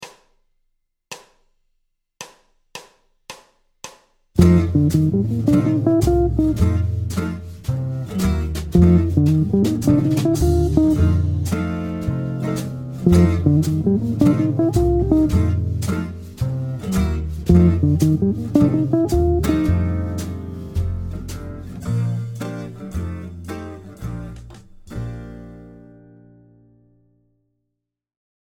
Pict domPhrases sur accords de dominante non altérés
F7 Phrase construite avec le mode de F Mixolydien (V ème degré de Bb Majeur) et une encapsulation sur 3 notes vers la Septième mineure.
Phrase-02-dom-F7.mp3